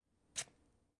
Звук ножа вонзающегося в дыню